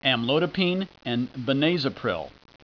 Pronunciation
(am LOE di peen & ben AY ze pril)